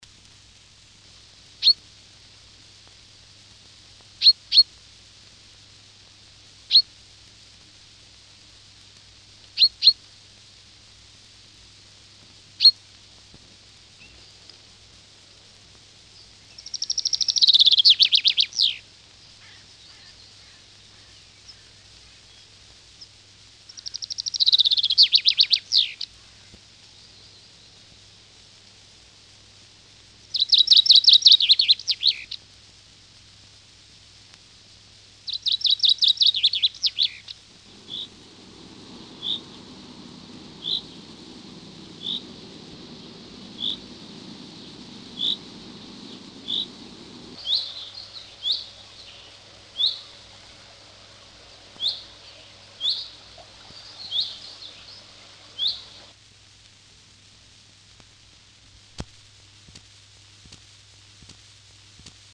Der Buchfink
1935 erschienen im Verlag von Hugo Bermühler in Berlin-Licherfelde insgesamt drei Schallplatten mit dem Titel „Gefiederte Meistersänger“, die die Singstimmen von Vögeln dokumentieren.
2401-1-B-Buchfink.mp3